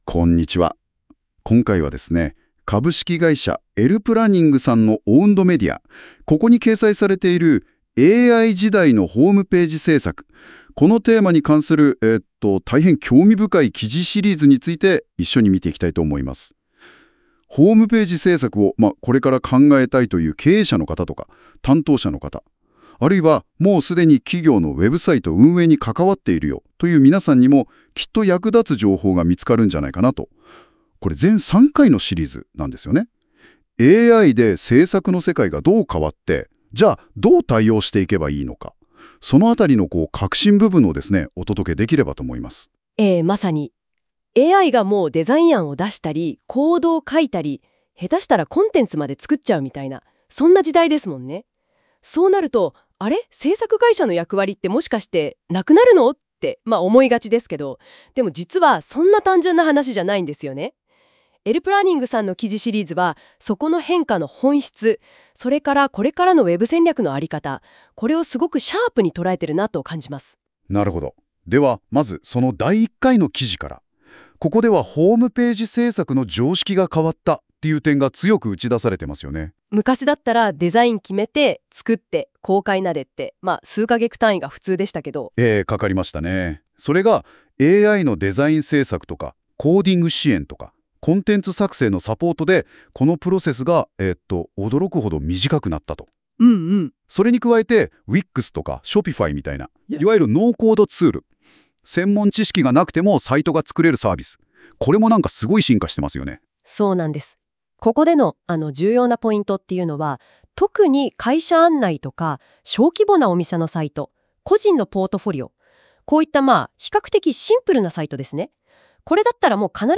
”ラジオ風”に記事ダイジェストを解説！
音声ダイジェストは、AIサービスの音声機能を活用し、自動的に生成された要約内容です。